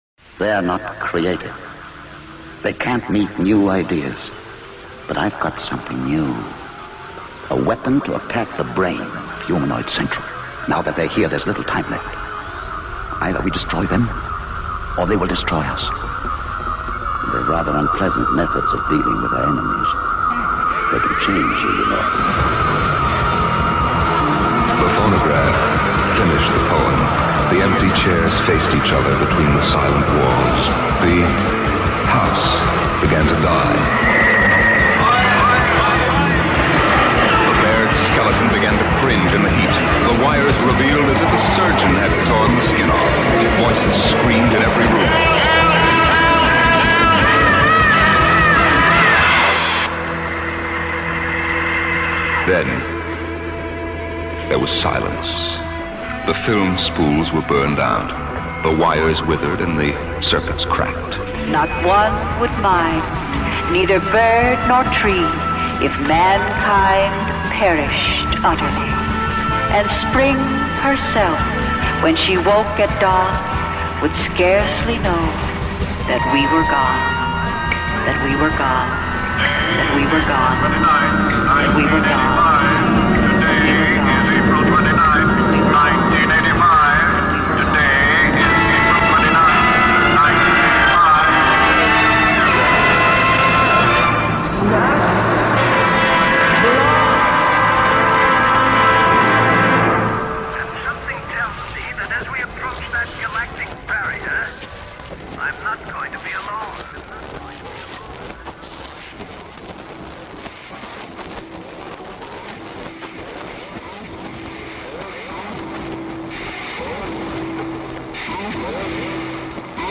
Jazz, punk, krautrock, psychedelia, noise, hardcore, folk, avant-garde & weird bleak rock & roll.